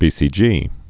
(bēsē-jē)